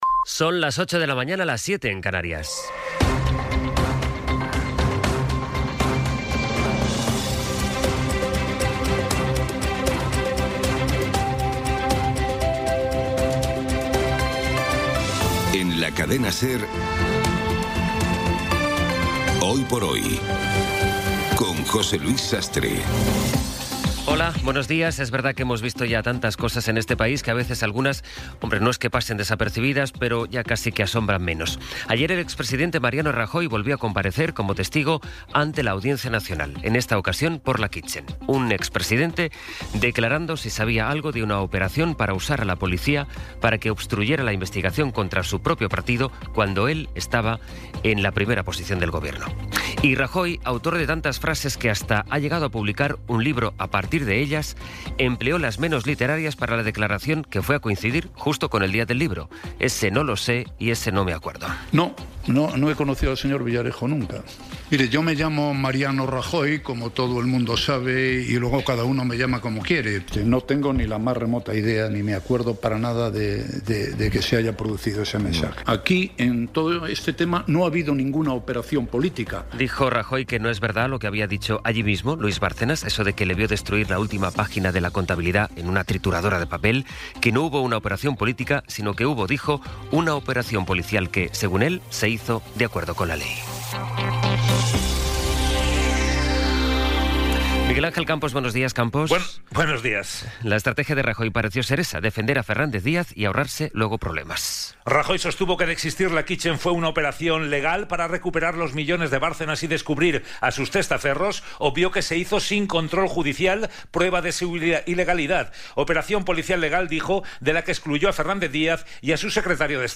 Las noticias de las 08:00 20:07 SER Podcast Resumen informativo con las noticias más destacadas del 24 de abril de 2026 a las ocho de la mañana.